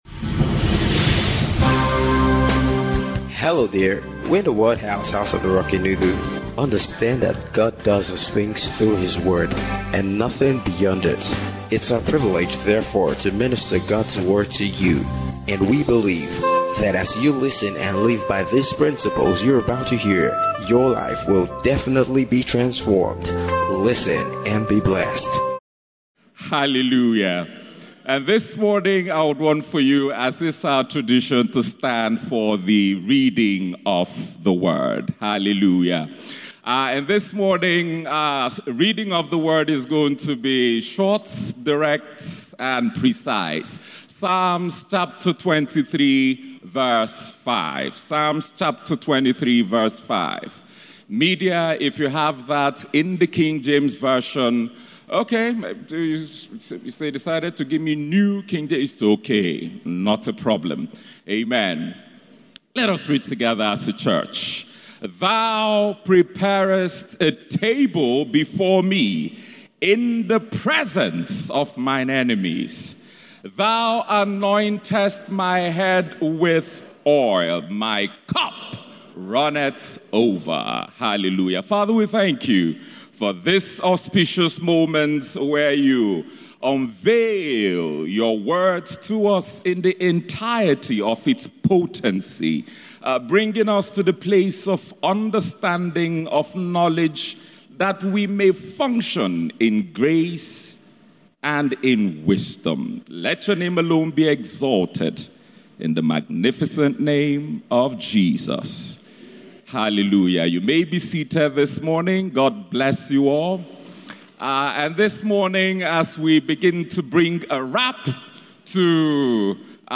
THOU ANNOINTEST MY HEAD WITH OIL - FRESH DEW SERVICE